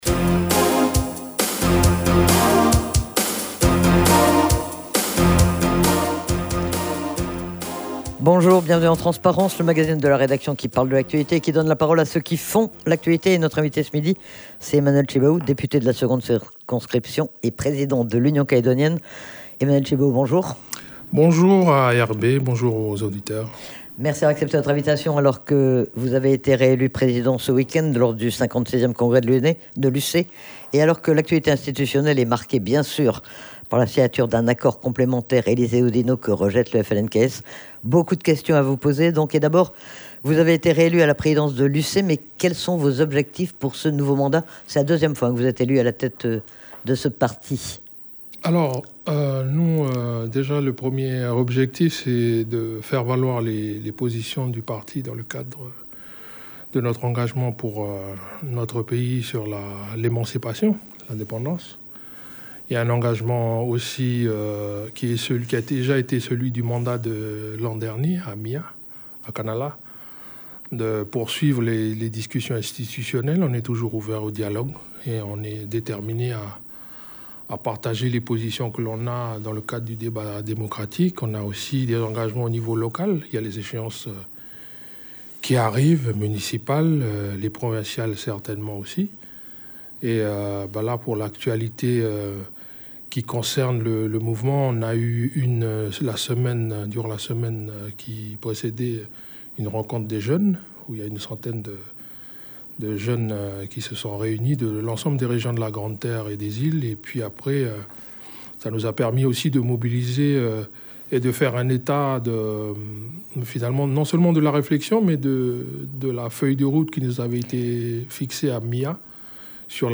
c'est Emmanuel Tjibaou, le président de l'Union calédonienne, qui était l'invité